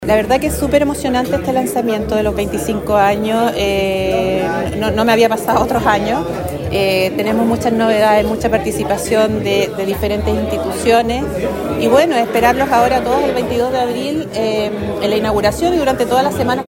En conferencia de prensa realizada en el Hotel Araucano, en el centro penquista, se dieron a conocer los principales hitos de esta edición especial, marcada por el fortalecimiento de la industria audiovisual regional y nacional, con el apoyo tanto del sector público como de la empresa privada.